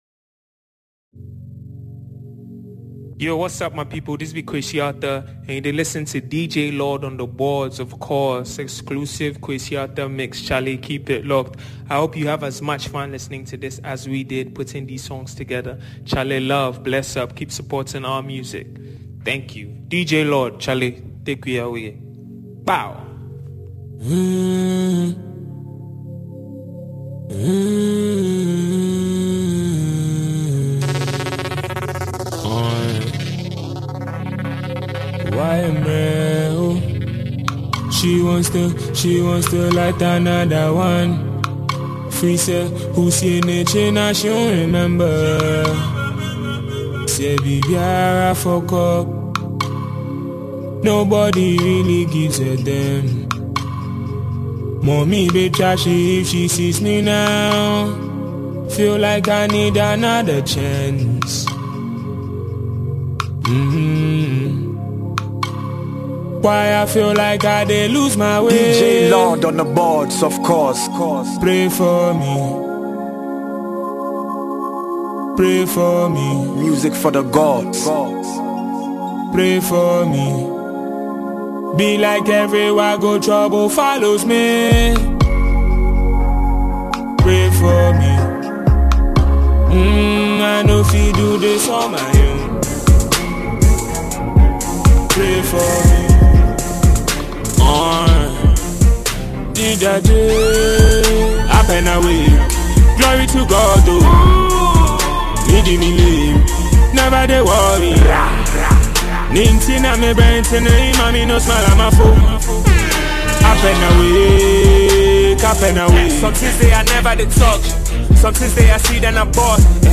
enjoy the vibrant sounds
a mix of great beats and catchy lyrics